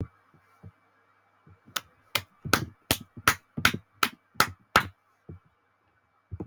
Sus Clapping Efeito Sonoro: Soundboard Botão
Sus Clapping Botão de Som